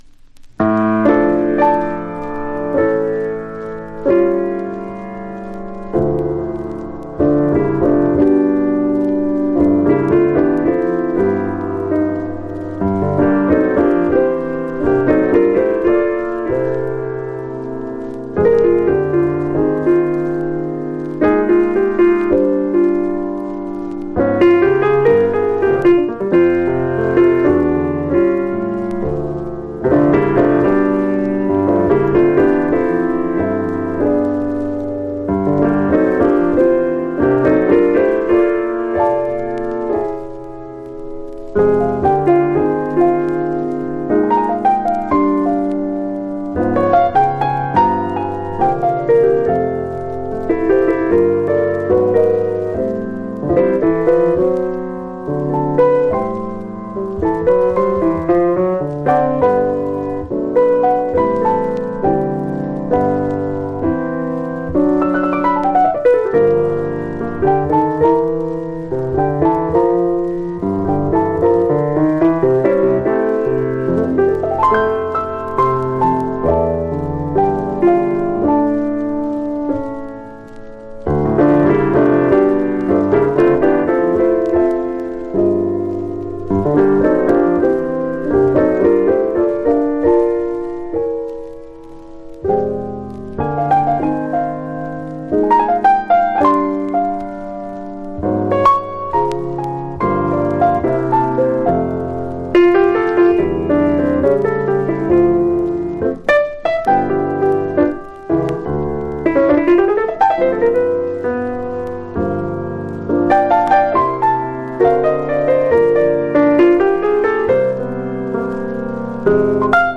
（プレス・小傷によりチリ、プチ音、サーノイズある曲あり）※曲名をクリックすると試聴できます。